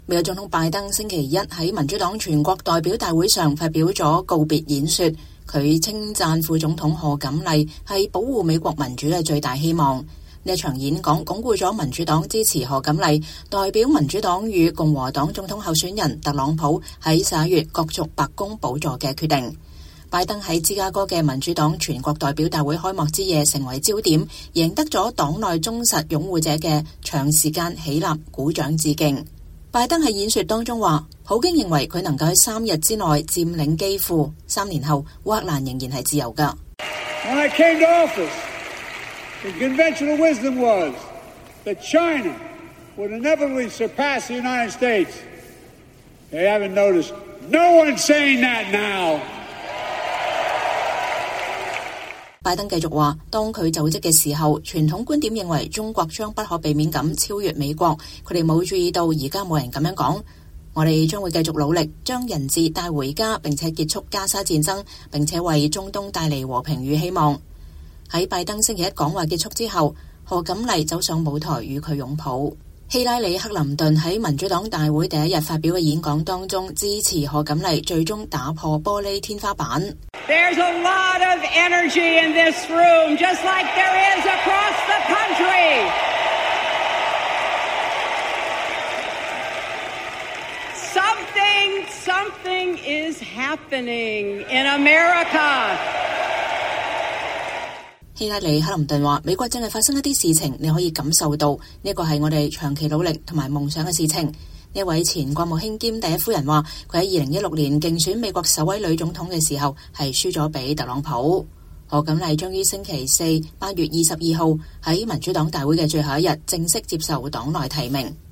拜登在芝加哥的民主黨全國代表大會開幕之夜成為焦點，贏得了黨內忠實擁護者的長時間起立鼓掌致敬。